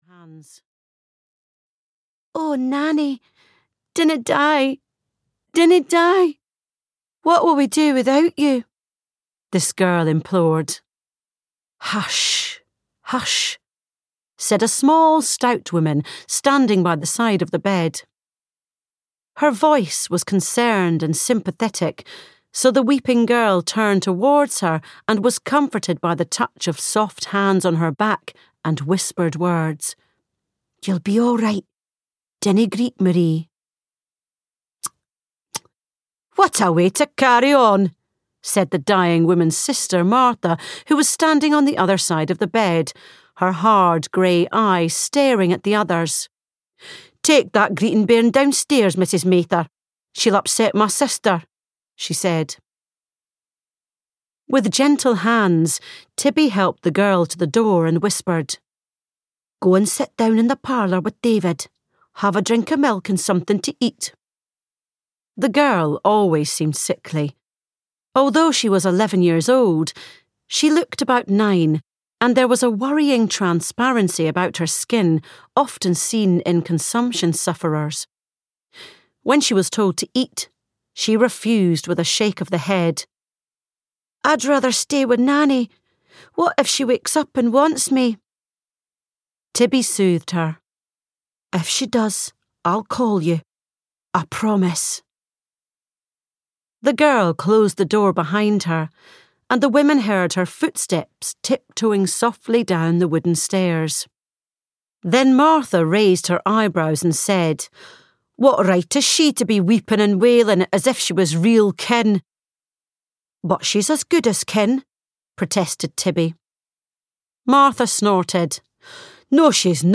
Wild Heritage (EN) audiokniha
Ukázka z knihy